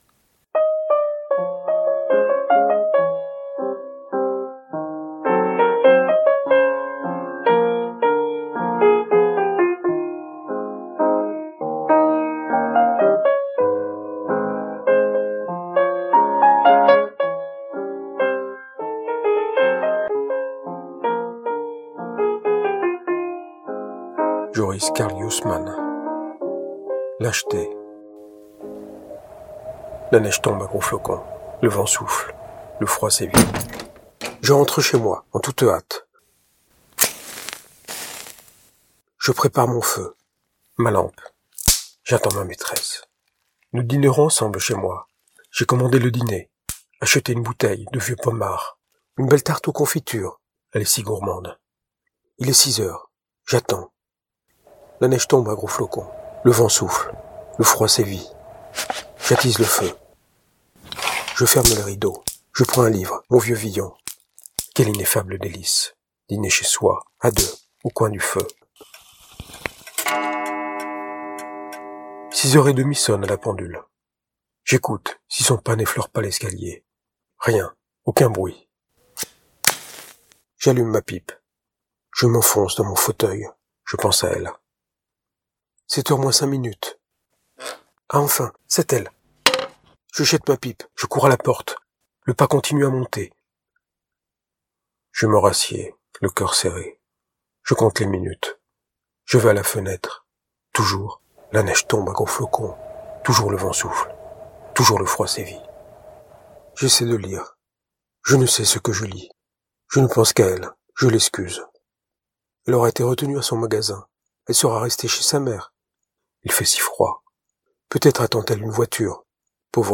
Livre audio gratuit publié le 2 février 2018.